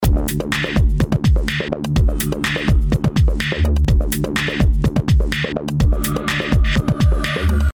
This track uses only native DSP’s and a couple of Blockfish compressors (the exact same instance of that plugin then and now).
Electrotrack-OLD.mp3